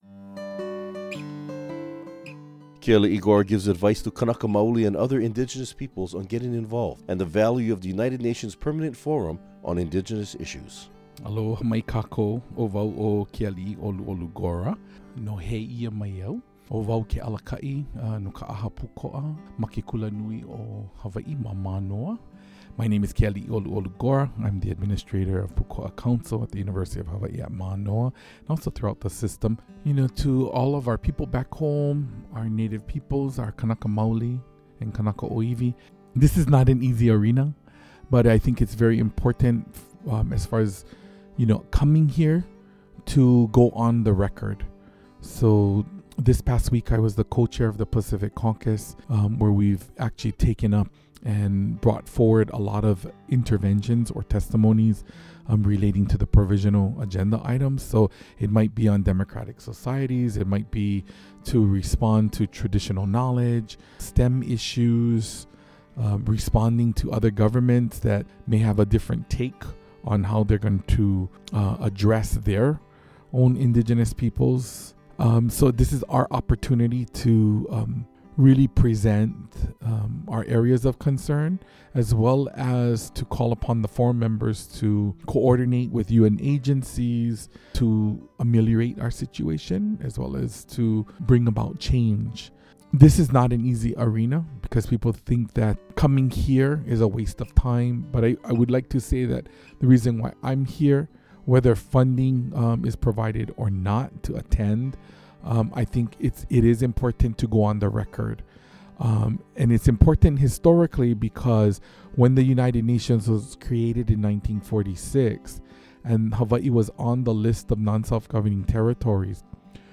Recording Location: UNPFII 2015
Type: Interview